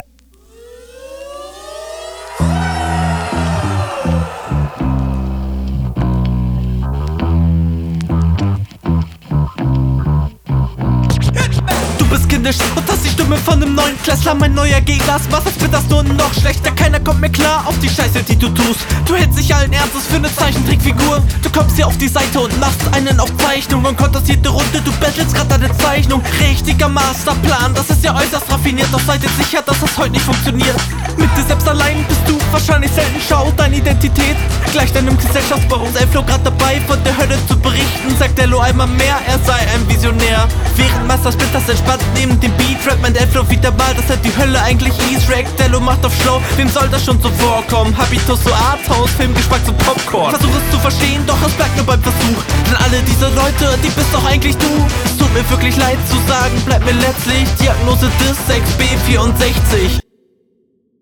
Stimme passt nicht optimal zum Beat bzw. da hätte man einen anderen Stimmeinsatz ausprobieren können.
Cooler Beat, guter Flow, viel Gegnerbezug.